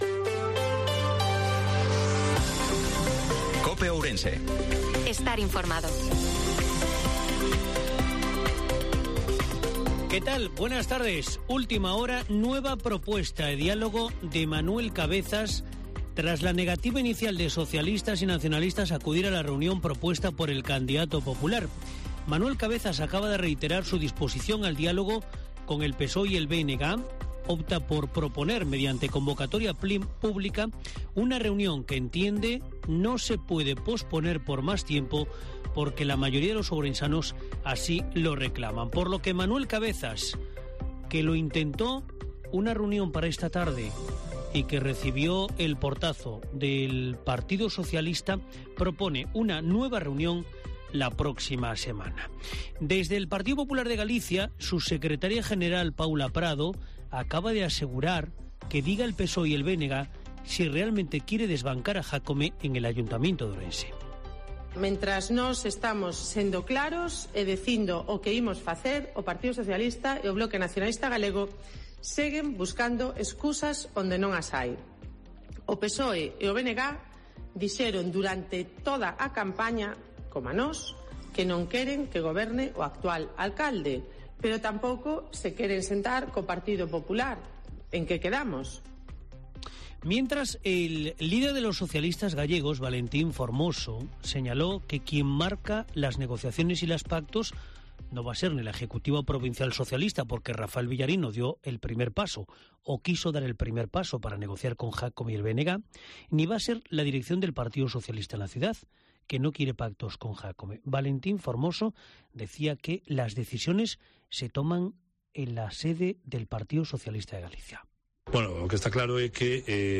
INFORMATIVO MEDIODIA COPE OURENSE-2/06/2023